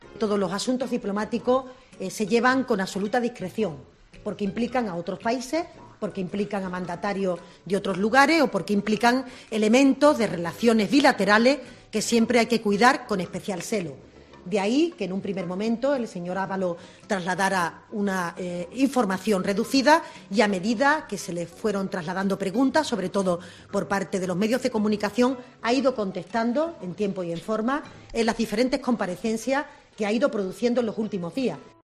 Declaraciones de la portavoz Montero